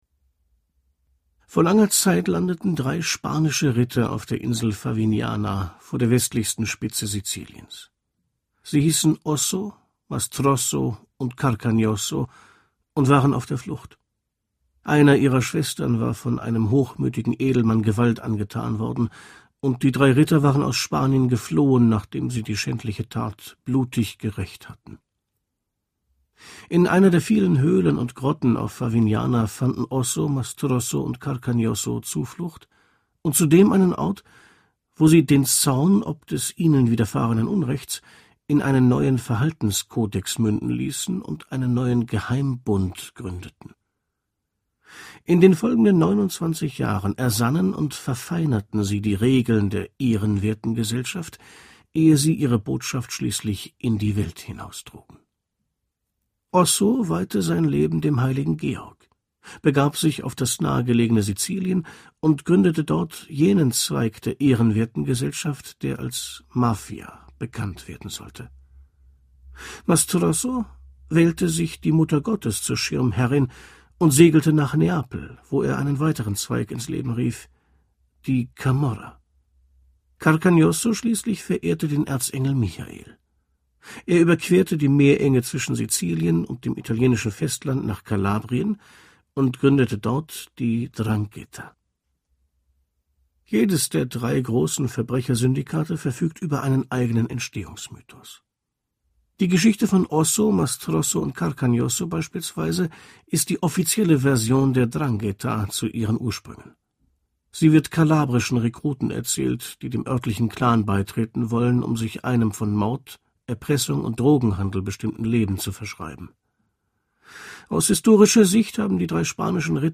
Hörbuch: Omertà.
gekürzte Lesung